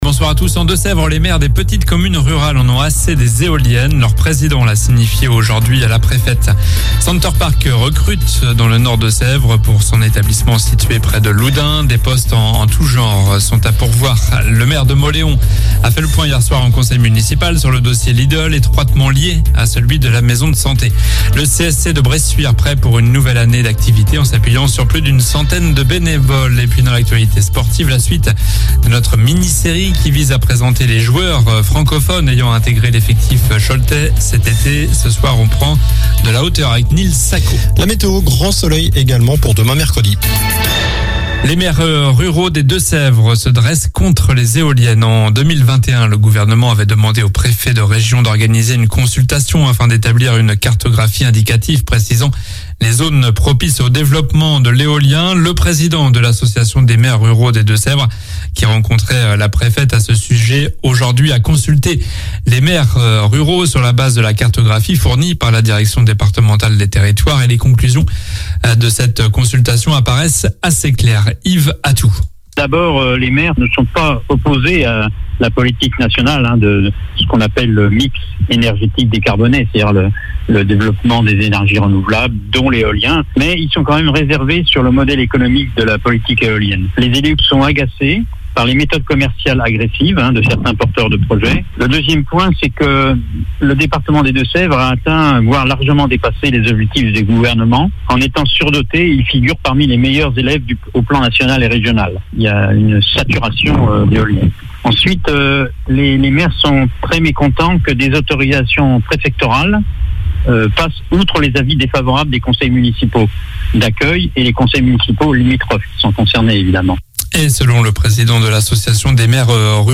Journal du mardi 20 septembre (soir)